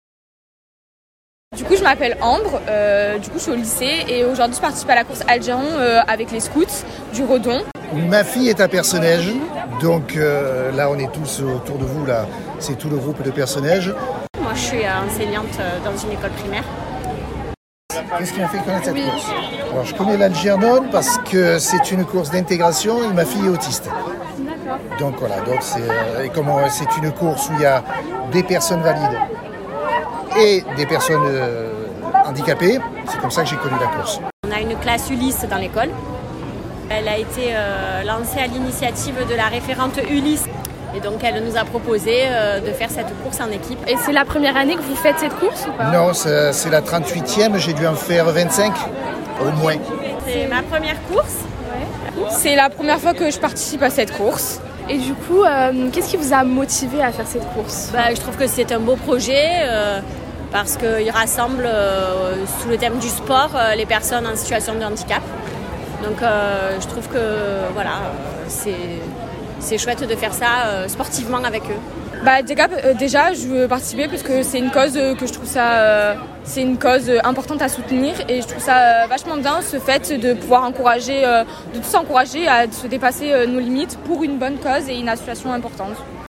Interview exclusive lors de la 38ème édition de la course Algernon
Interview-Course-Algernon-.mp3